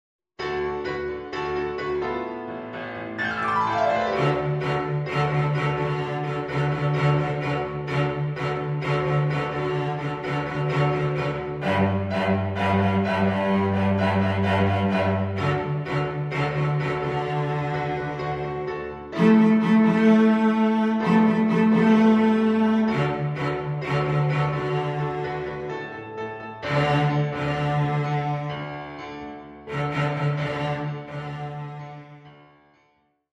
Ten easy ‘high-voltage’ pieces for Cello and Piano.
• Attractive original fun-based melodies.